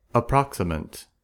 Ääntäminen
Ääntäminen US
IPA : /əˈprɑksɪmənt/